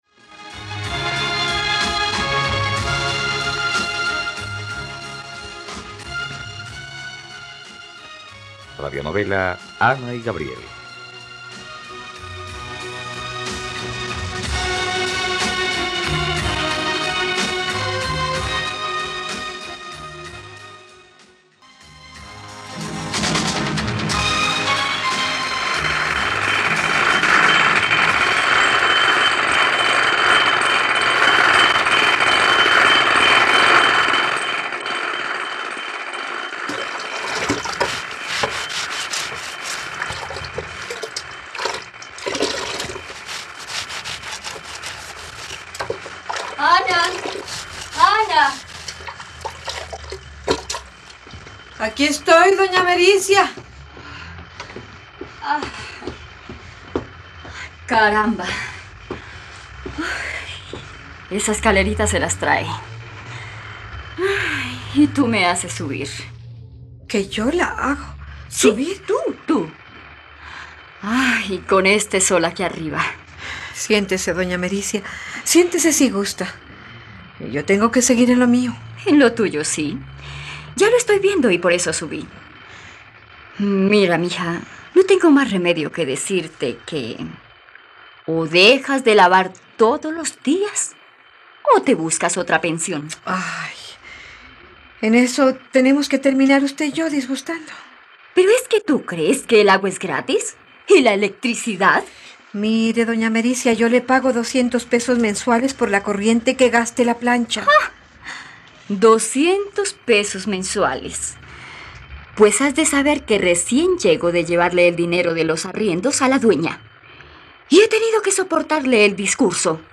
..Radionovela. Escucha ahora el primer capítulo de la historia de amor de Ana y Gabriel en la plataforma de streaming de los colombianos: RTVCPlay.